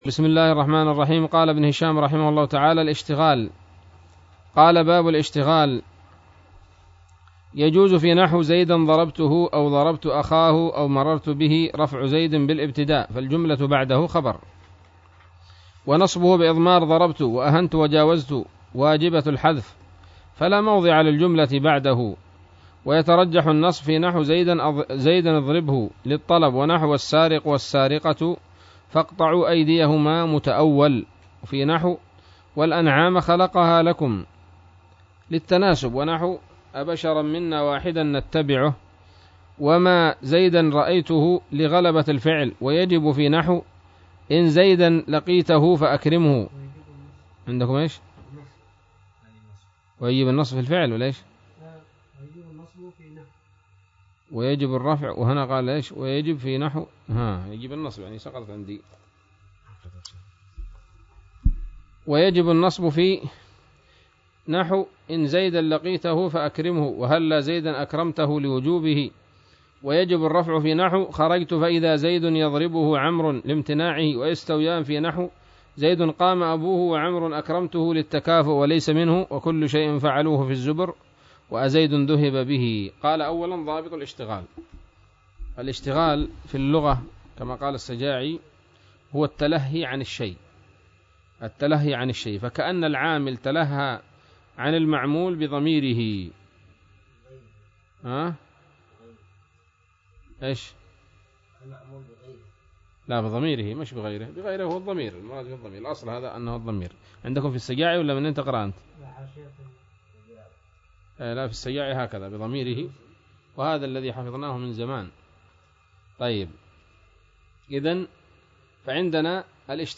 الدرس الثامن والسبعون من شرح قطر الندى وبل الصدى